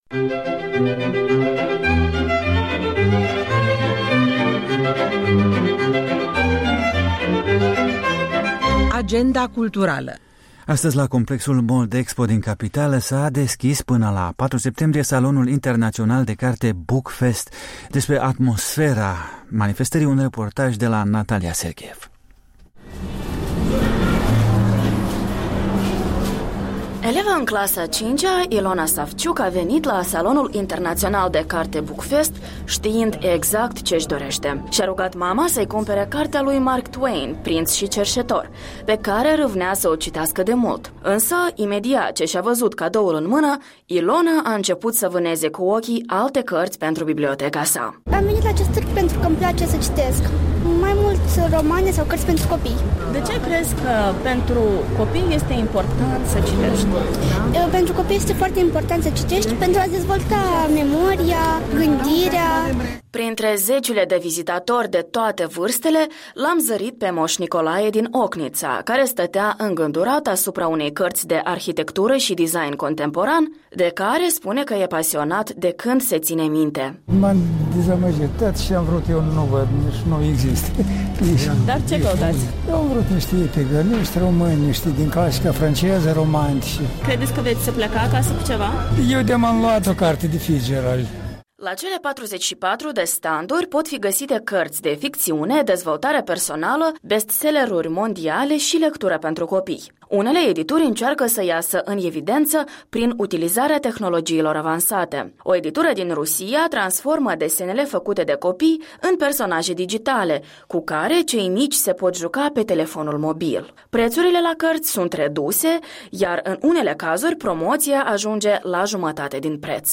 Un reportaj de la Salonul cărții „Bookfest” de la Chișinău.
Un reportaj de la fața locului.